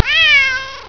neko.wav